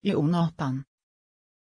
Pronunciación de Yonatan
pronunciation-yonatan-sv.mp3